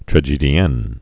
(trə-jēdē-ĕn)